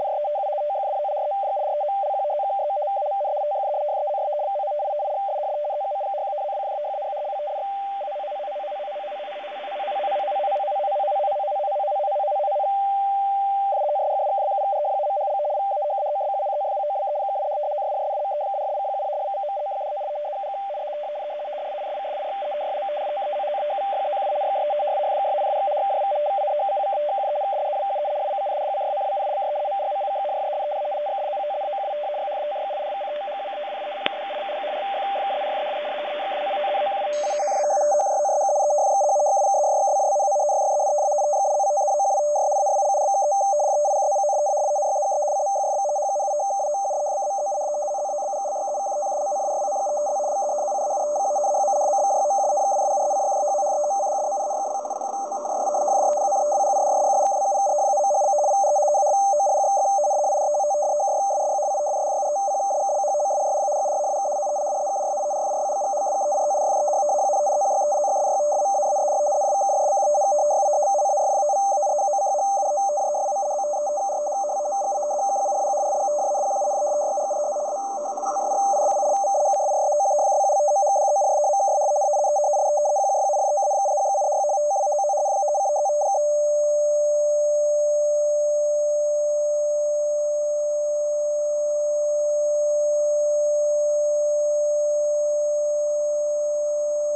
Передача FSK2, dF=200 Hz, V=75bps